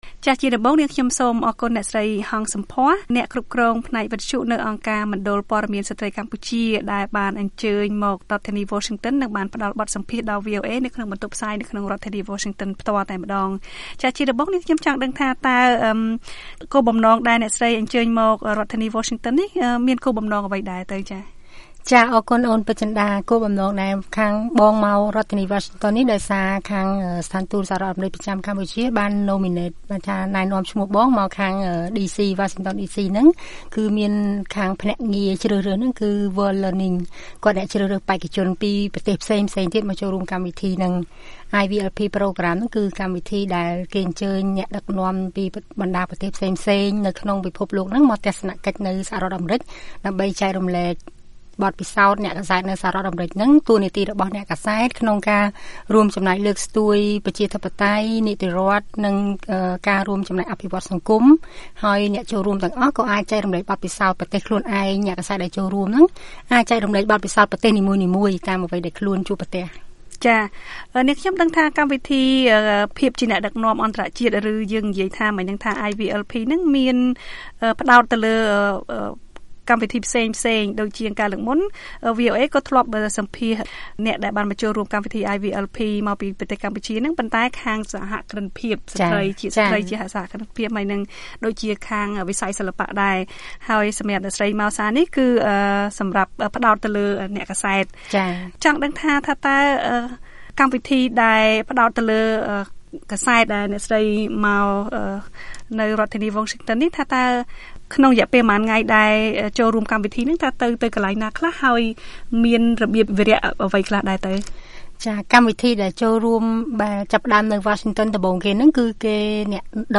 បទសម្ភាសន៍ VOA៖ ការប្រឈមរបស់អ្នកសារព័ត៌មានជាស្រ្តី និងសារៈសំខាន់នៃតួនាទីសារព័ត៌មាននៅក្នុងការអភិវឌ្ឍសង្គម